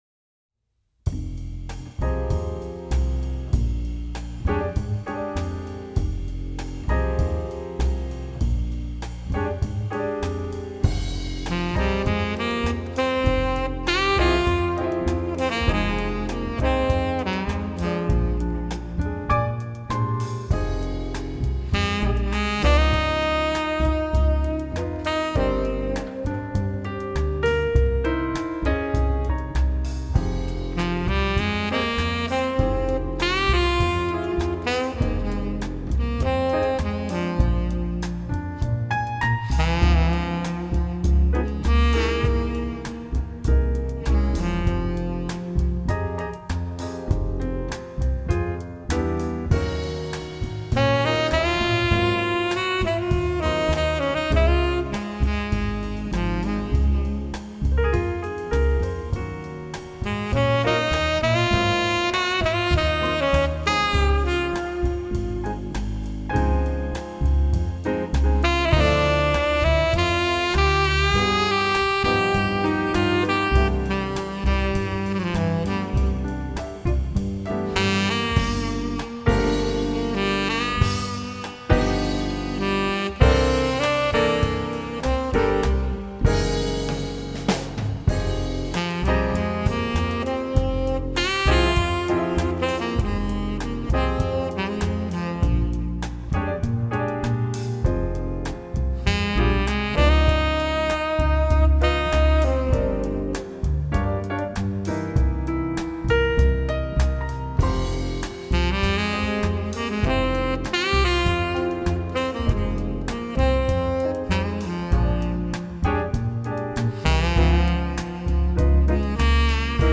风格流派：Pop & Jazz
作品大多以清新抒情的风格为主，接近于冥想、身心放松类音乐。
四重奏小乐队，他们的演奏松弛、诙谐、伤感、默契，泰然自若，令